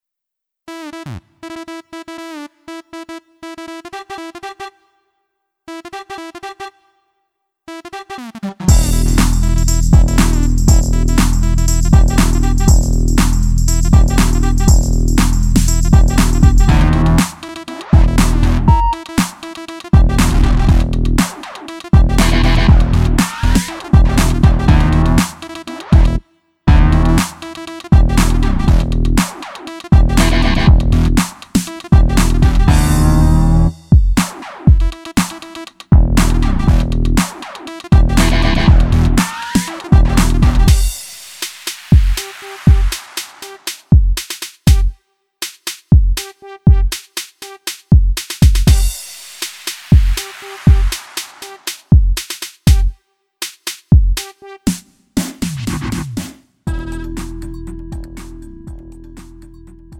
음정 -1키 2:53
장르 가요 구분